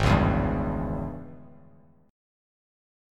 G#M7sus4#5 chord